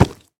sounds / mob / piglin / step4.ogg
step4.ogg